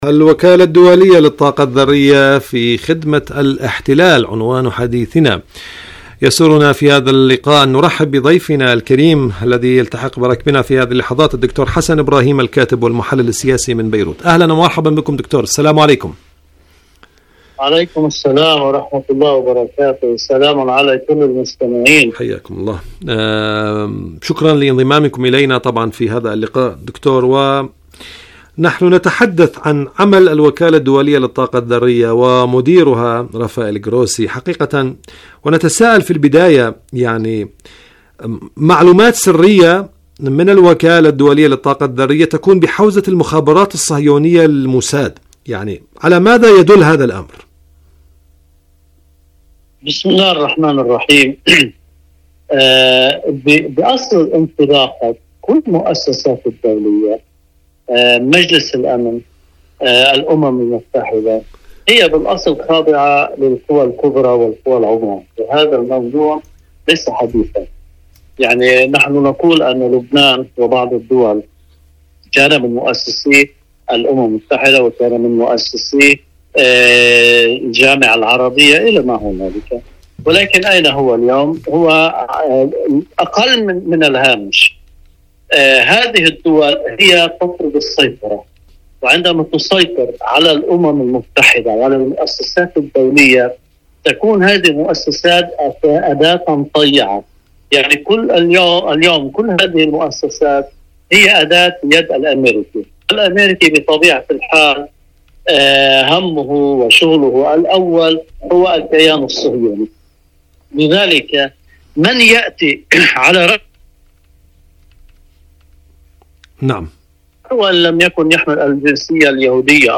مقابلات الوكالة الدولية للطاقة الذرية في خدمة الاحتلال برامج إذاعة طهران العربية برنامج حدث وحوار مقابلات إذاعية شاركوا هذا الخبر مع أصدقائكم ذات صلة آليات إيران للتعامل مع الوكالة الدولية للطاقة الذرية..